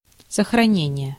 Ääntäminen
Synonyymit экономия Ääntäminen Haettu sana löytyi näillä lähdekielillä: venäjä Käännös Ääninäyte Substantiivit 1. preservation US 2. conservation 3. saving US 4. safe-keeping 5. storage 6. retention Translitterointi: sohranenije.